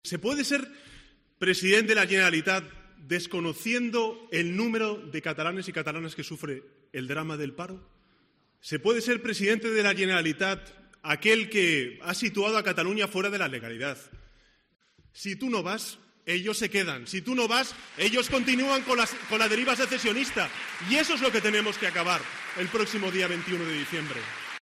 El líder del PSOE ha participado en un mitin del PSC en el Palacio de Congresos de Tarragona ante cerca de 500 personas, para arropar al candidato socialista a la Presidencia de la Generalidad, Miquel Iceta, junto al alcalde de la ciudad, Josep Fèlix Ballesteros, y la cabeza de lista en Tarragona, Rosa María Ibarra.